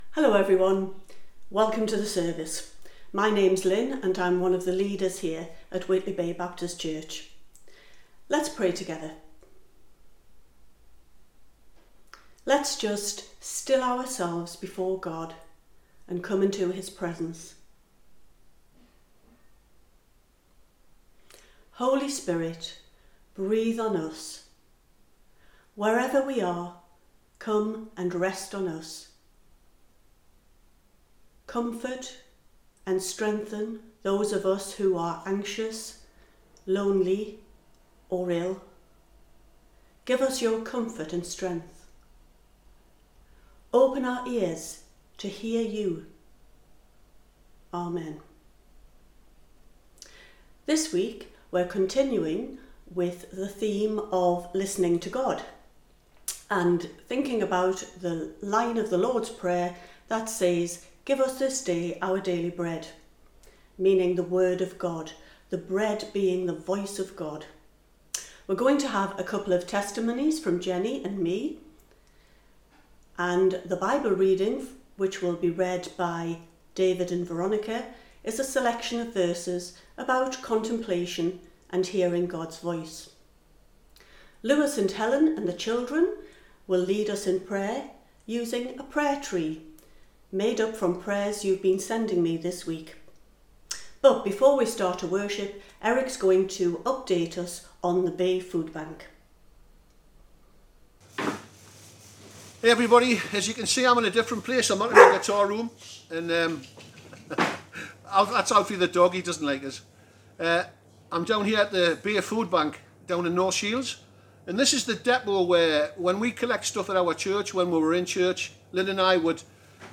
Pre-recorded video and audio.
Morning Service